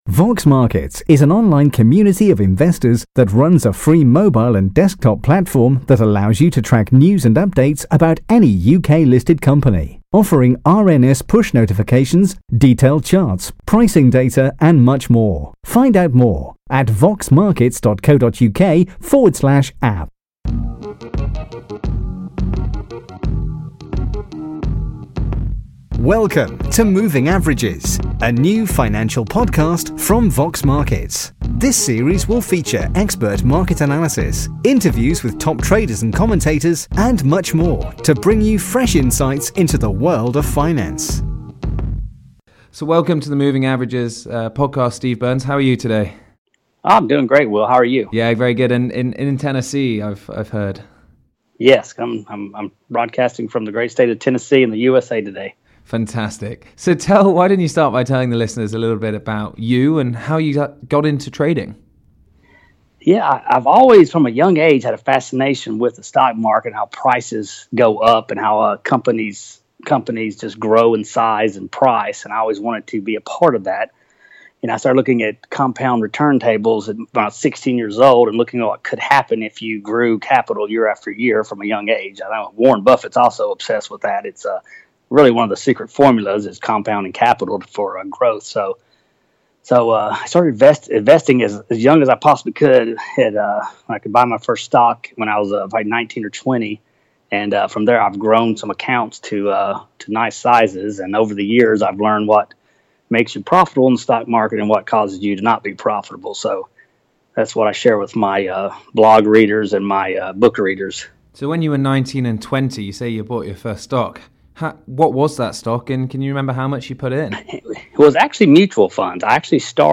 Moving Averages podcast interview